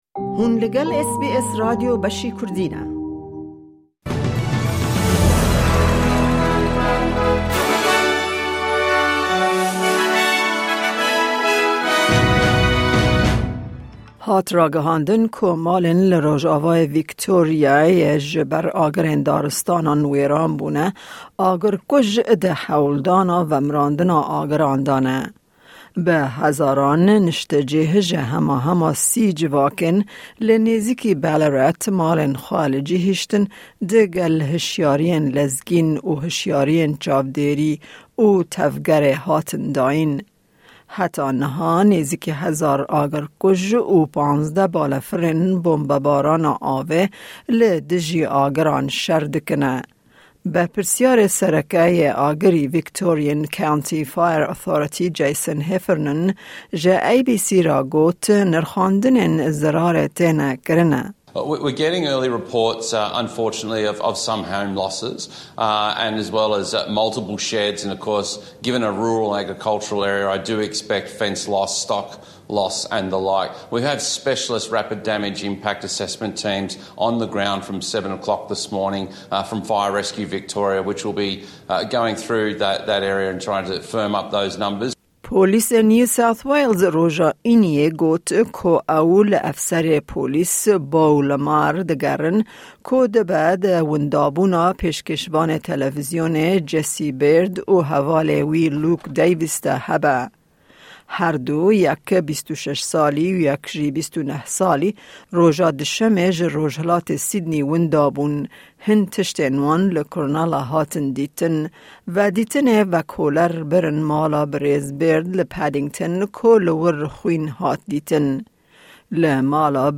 Nûçeyên Hefteyê